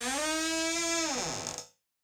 Door Hinge Creaking Door.wav